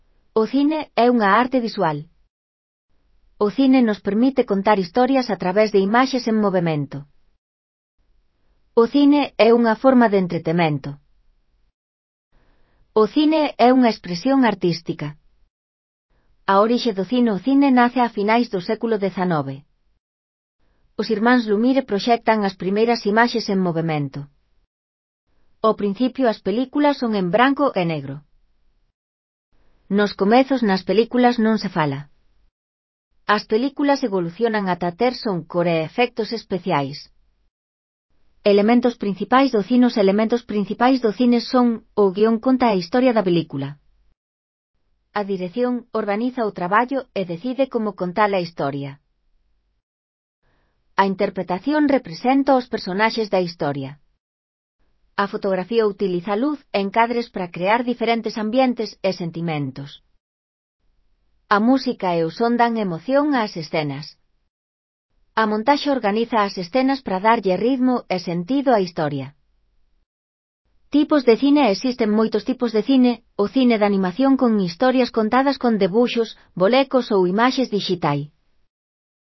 Lectura facilitada
Elaboración propia (proxecto cREAgal) con apoio de IA, voz sintética xerada co modelo Celtia..